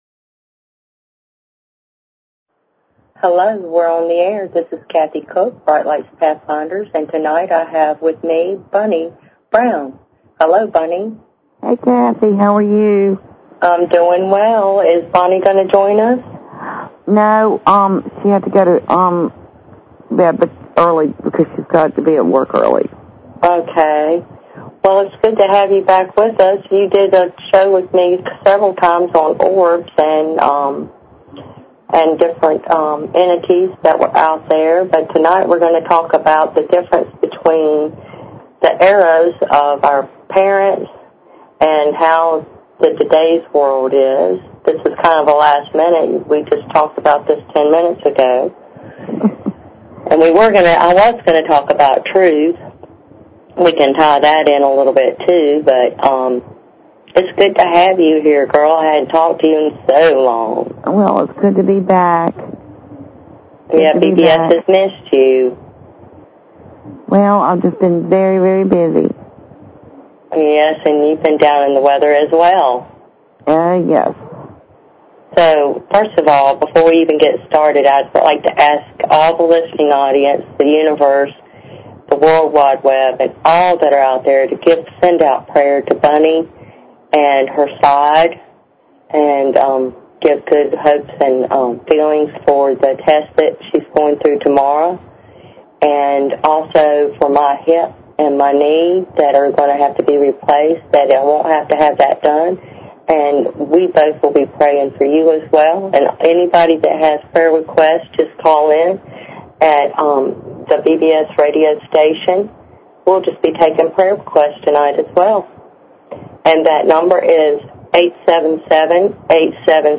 Talk Show Episode, Audio Podcast, Brightlights_Pathfinders and Courtesy of BBS Radio on , show guests , about , categorized as